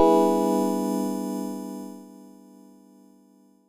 Pulpit Chord B2.wav